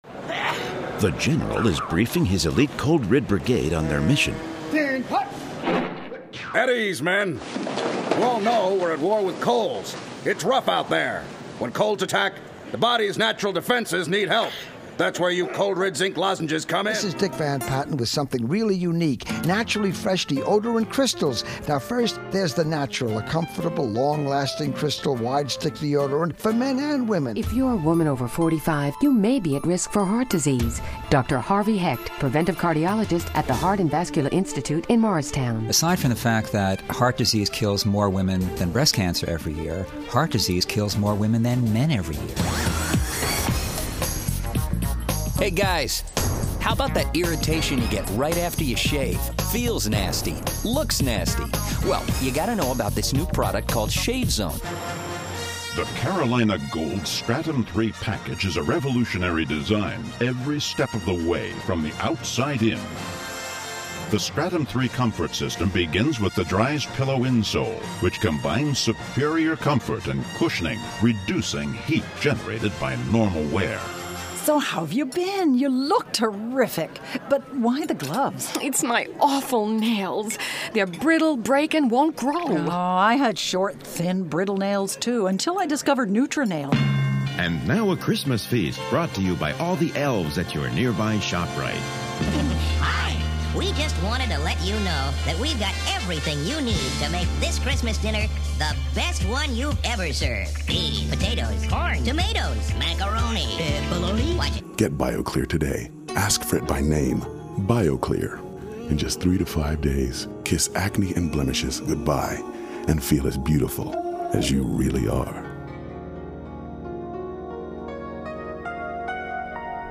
Here are a few montages of commercials and narrations … with a third leg.
Commercial Demo